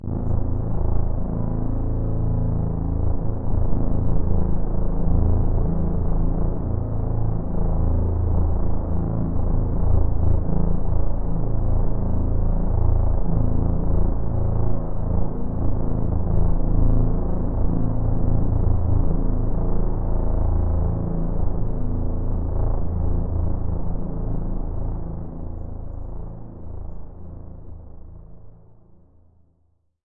低音 " 不稳定的低音氛围
描述：一个用Audacity从头开始制作的合成低音环境。
Tag: 低音的氛围 低音调